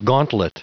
Prononciation du mot gauntlet en anglais (fichier audio)
Prononciation du mot : gauntlet
gauntlet.wav